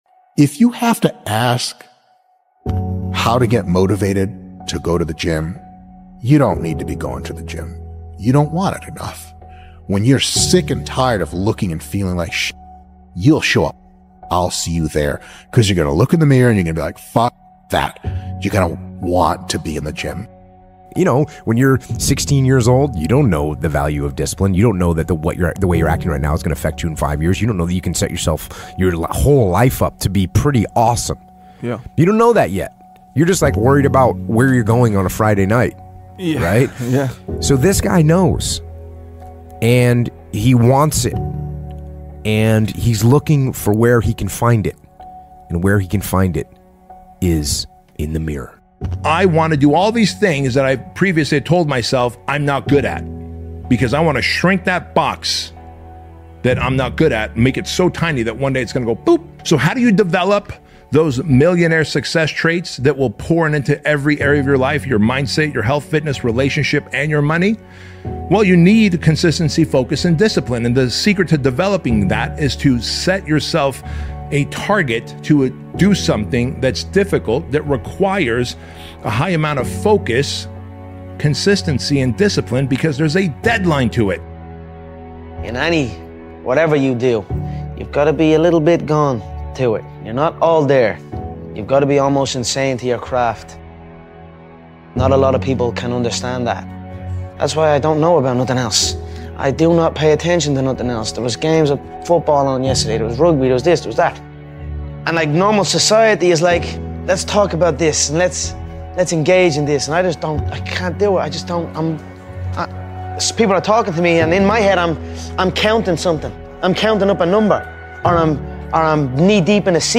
Daily Discipline: Mental Warfare Speech to Forge an Iron Mind and Body
All ads in Quote of Motivation begin right at the start of each episode so nothing interrupts the moment you settle in, breathe, and feel that familiar spark rising.